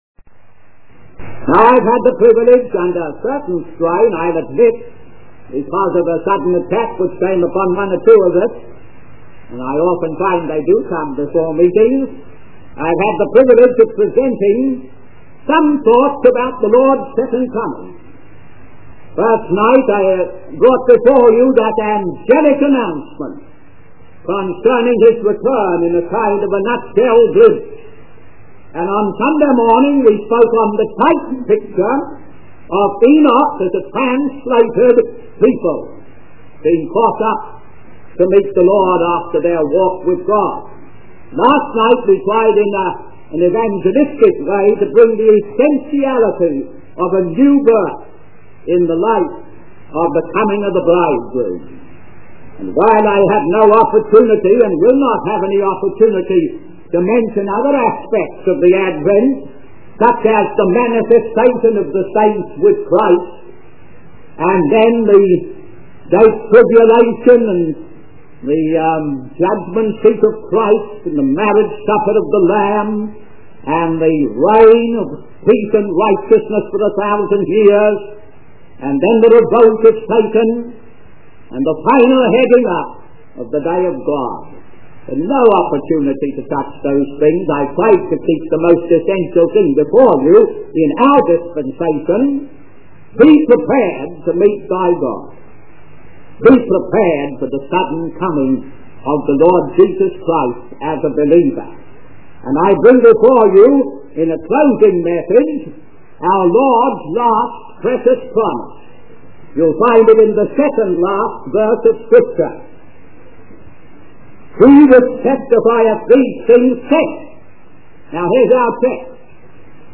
In this sermon, the preacher focuses on the book of Revelation and its significance as the testimony of Jesus Christ.